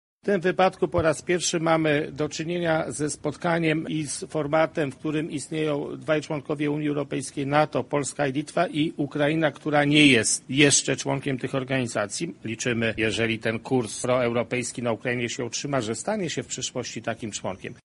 Trójkąt Lubelski to już konkretna decyzja – tak Jacek Czaputowicz mówił podczas spotkania na Placu Litewskim.
O tym, co wyróżnia ten układ na tle innych ugrupowań międzynarodowych, mówi Minister Spraw Zagranicznych Jacek Czaputowicz: